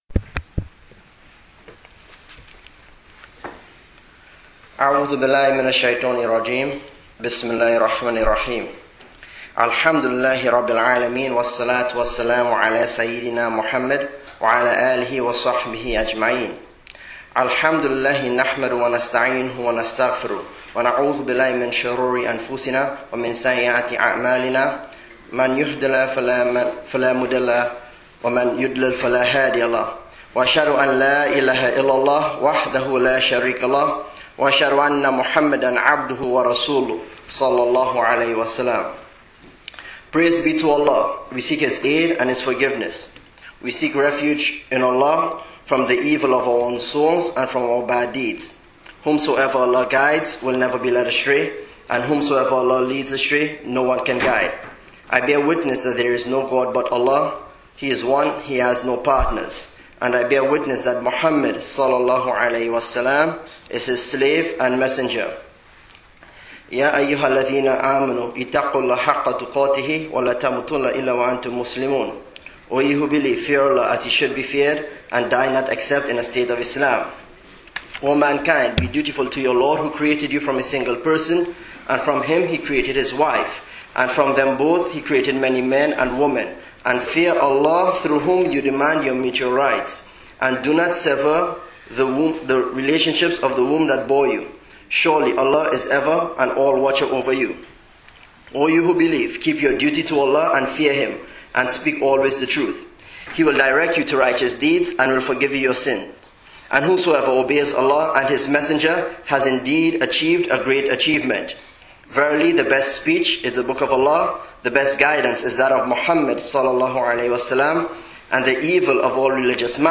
Jumu'ah Khutbah: Strengthening our Social Relationships (Masjid Quba | 3/18/2011)